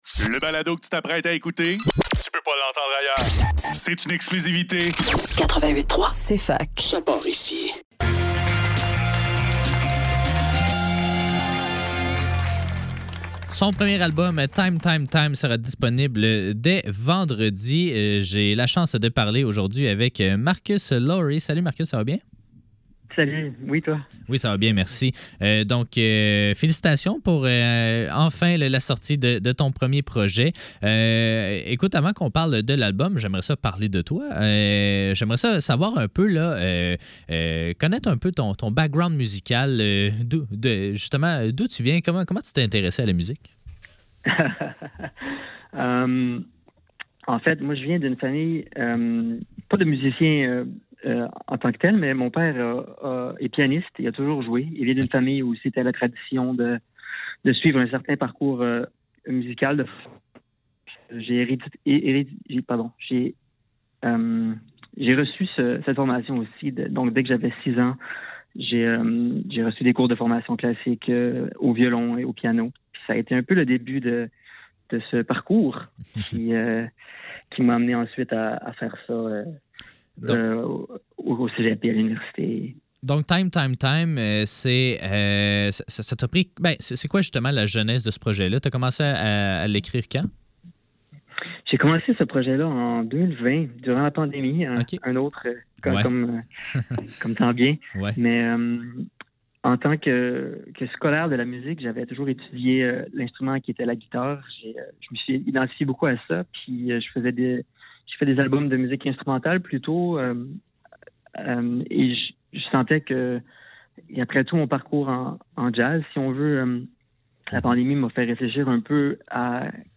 Faudrait que le tout l'monde en parle - Entrevue